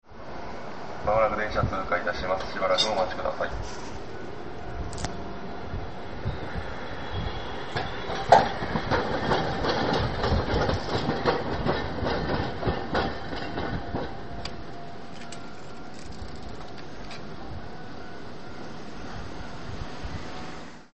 住吉駅の２番線を出て交差点をわたる恵美須町行き。
どこからアナウンスしているのか、電車が来ると、「電車が通過します」という声がします。